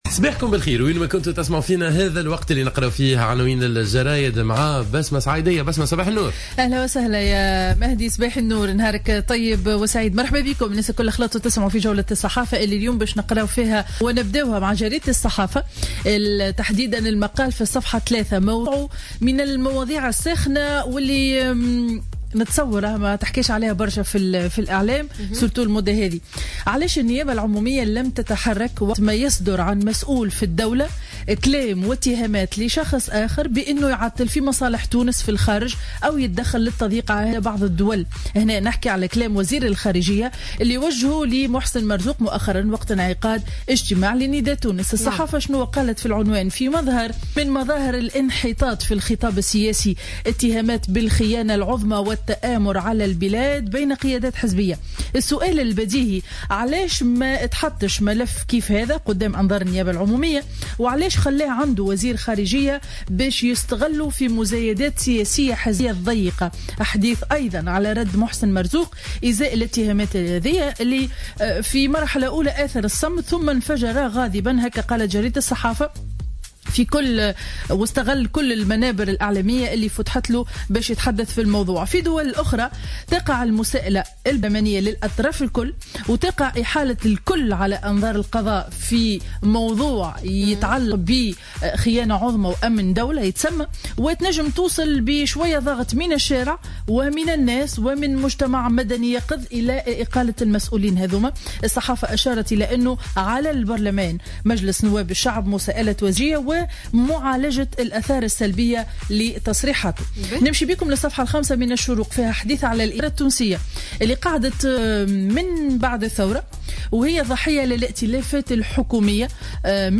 Revue de presse du mercredi 30 décembre 2015